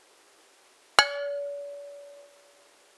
We repeat the previous measurements, same microphone, same software, same drumstick, preferably in the same room and at the same time.
The difference is huge, the duration is much shorter, the shape of the sound reproduces the typical pattern of the object under measurement, but only one or two beats are noticeable.
The spectrum after damping is lower in level but richer in harmonics, which in any case are reduced to less than 3 seconds compared to about 40 originally, listen to the two sounds.
PX2-hit-platter-dump-4s.mp3